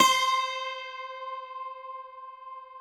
53q-pno14-C3.aif